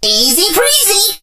lou_kill_vo_02.ogg